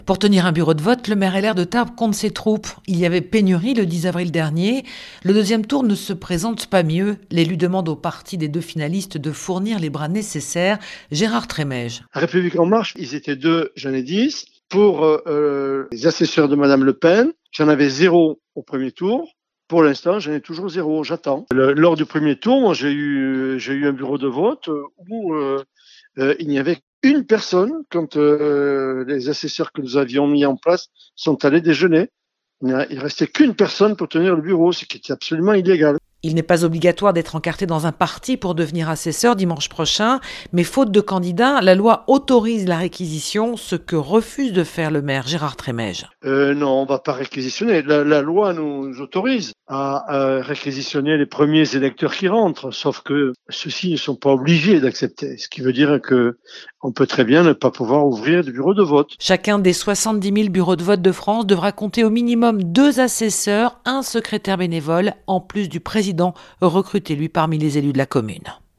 Pour tenir un bureau de vote, le maire LR de Tarbes, Gérard Trémège, compte ses troupes.